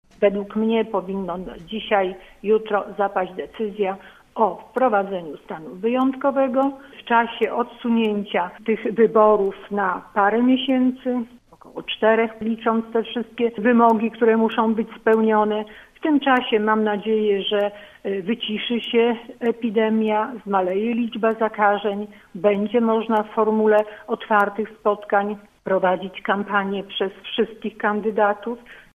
W Rozmowie Punkt 9, radna sejmiku województwa lubuskiego i przewodnicząca lubuskich struktur Nowoczesnej wskazywała, że proces wyborczy, ze względu na epidemię koronawirusa, jest utrudniony.